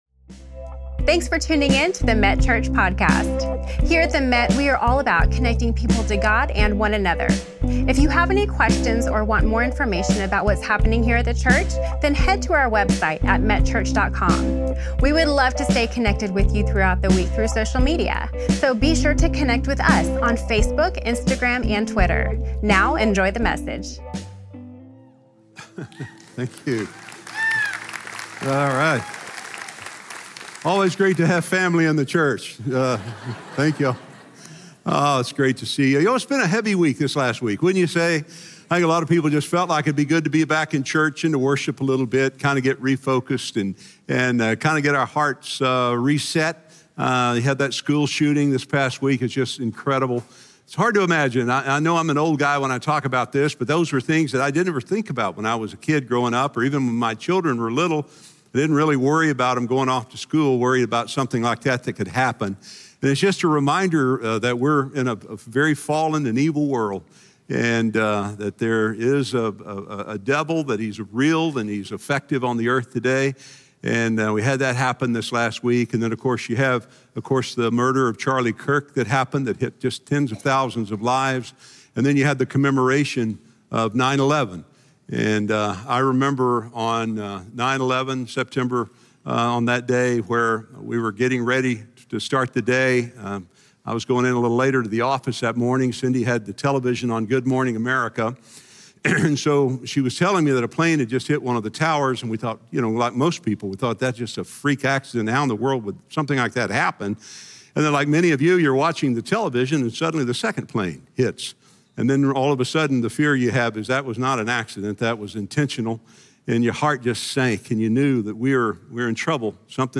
This sermon unpacks what baptism truly means, why it matters, and how it symbolizes our faith in Jesus.